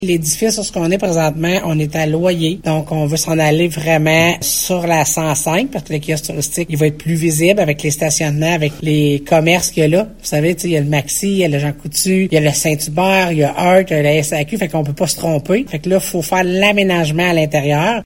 La MRC a également octroyé un contrat à la firme Louis Roy & Associés Inc. concernant l’aménagement du bâtiment Gyva afin d’en faire le kiosque touristique de Maniwaki. La préfète de la Vallée-de-la-Gatineau, Chantal Lamarche, précise pourquoi cet emplacement était idéal :